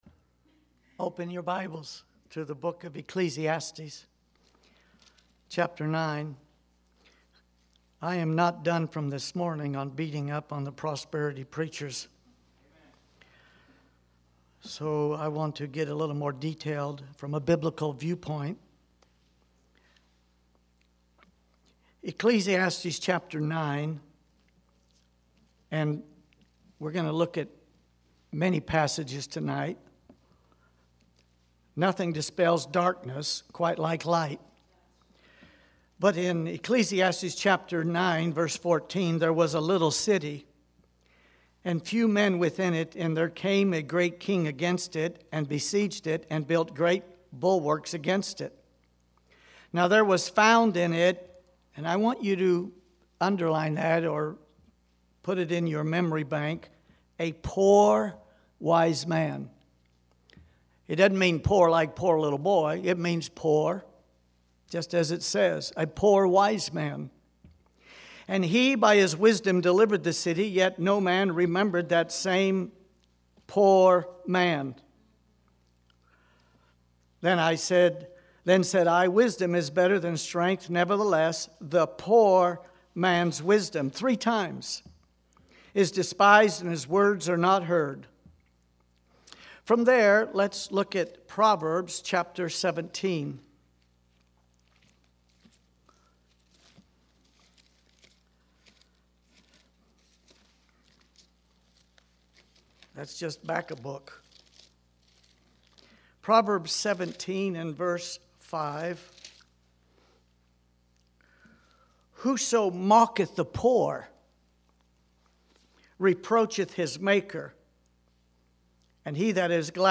Evening Sermons